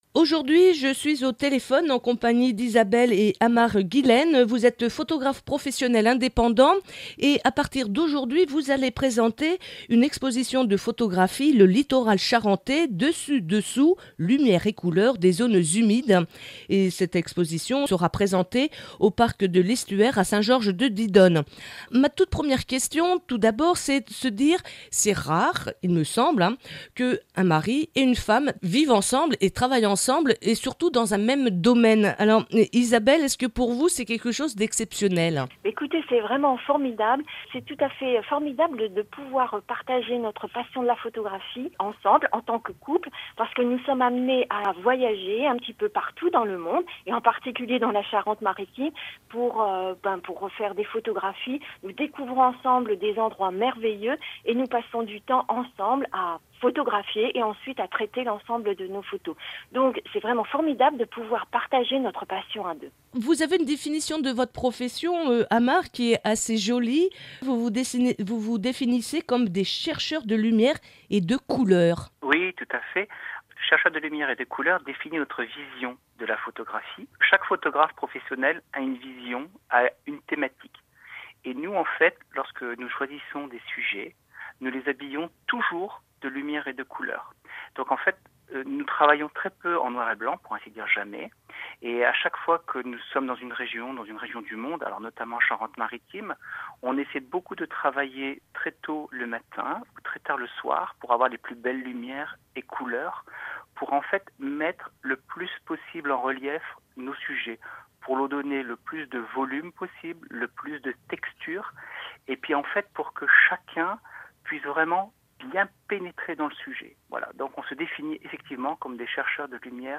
Notre interview pour RCF.